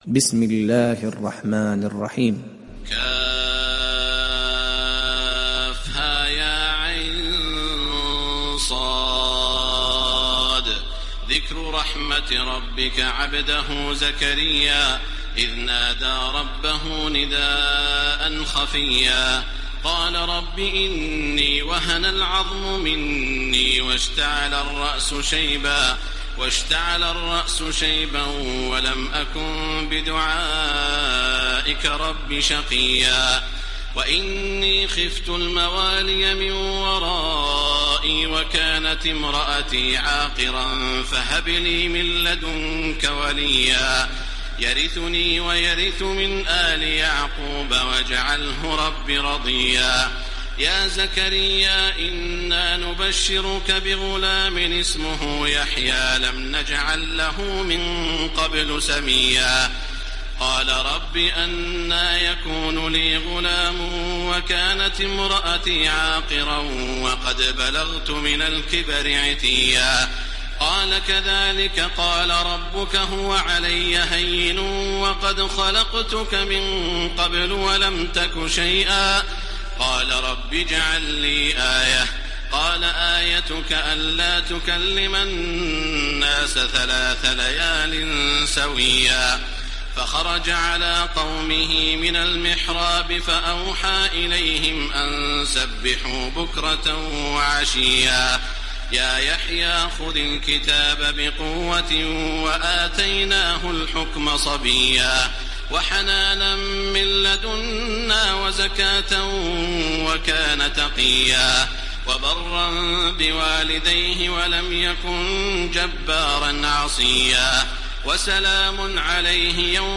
Surat Maryam Download mp3 Taraweeh Makkah 1430 Riwayat Hafs dari Asim, Download Quran dan mendengarkan mp3 tautan langsung penuh
Download Surat Maryam Taraweeh Makkah 1430